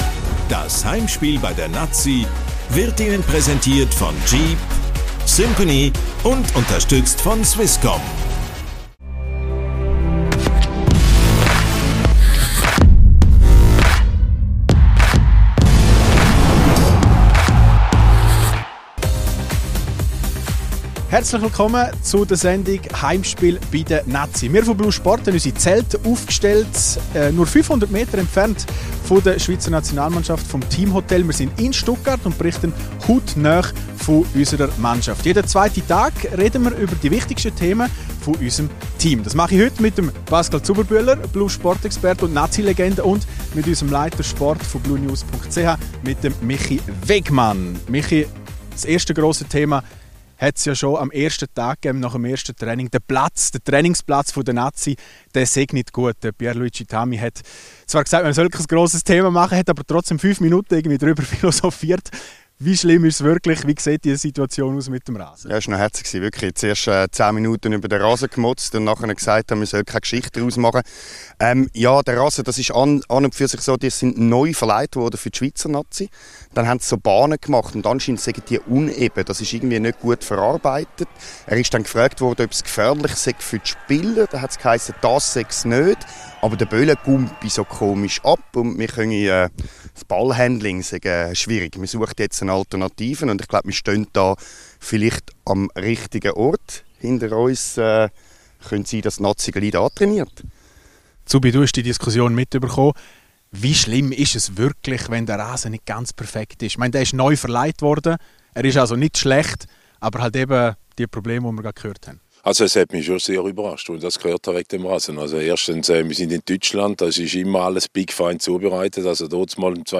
Heimspiel bei der Nati – der EM-Talk von blue Sport.